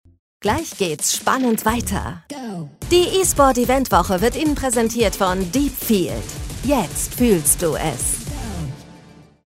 sehr variabel
Commercial (Werbung)